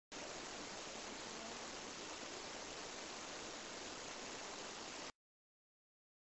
It sounded like it came from about 4 ft. high in the air and located close to the doorway of the furniture room.